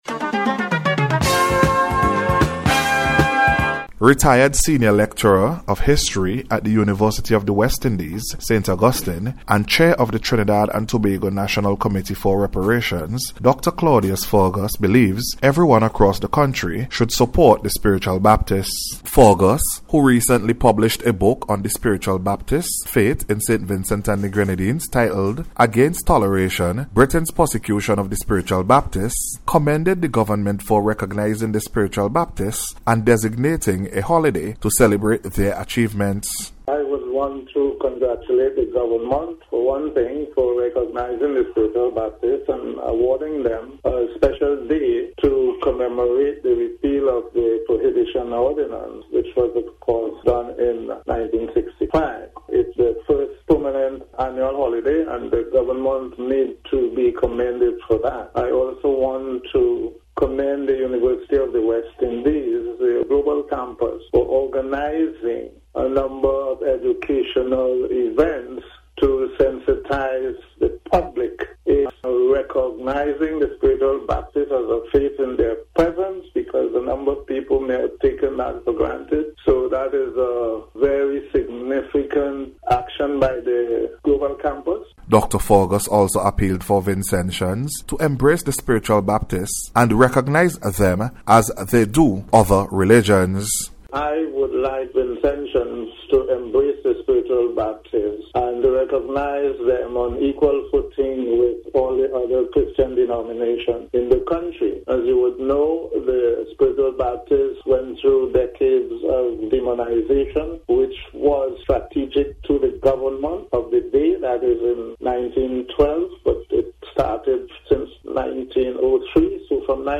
NBC’s Special Report- Monday 5th May,2025